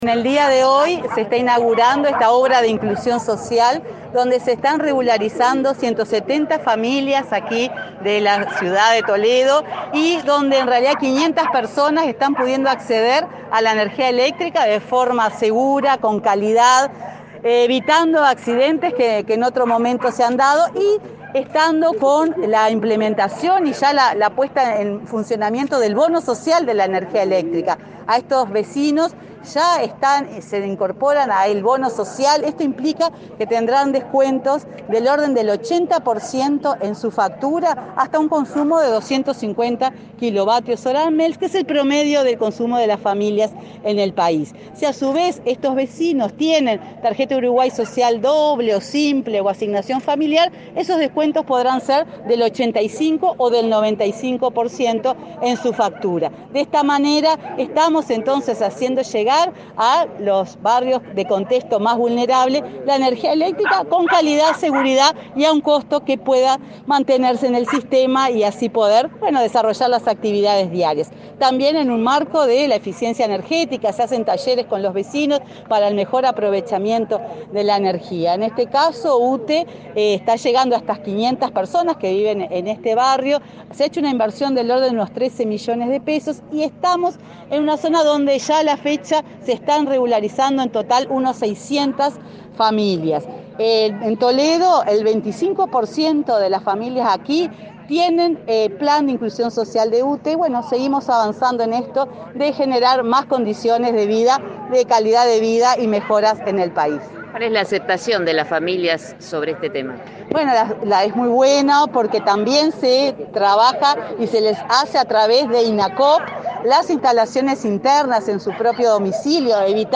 La presidenta de UTE, Silvia Emaldi, dialogó con Comunicación Presidencial acerca de los nuevos servicios eléctricos inaugurados en Toledo, Canelones,